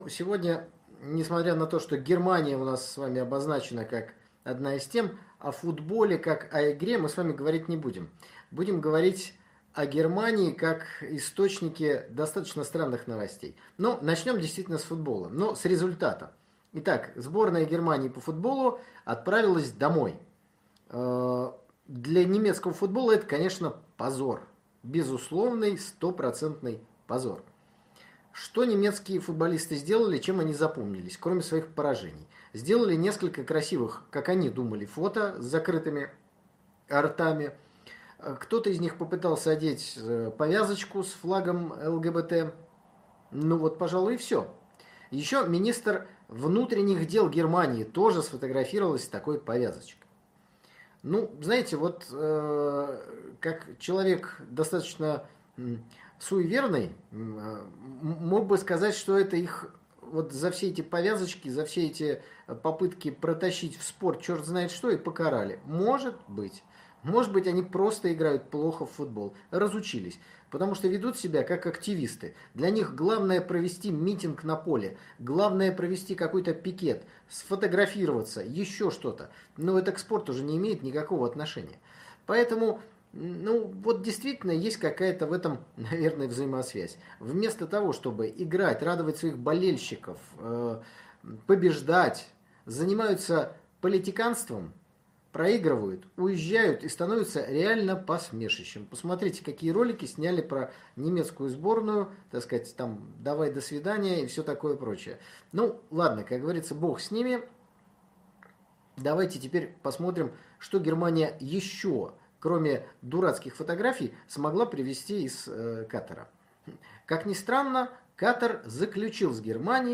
В очередном прямом эфире выходного дня поговорили о футболе и экономике.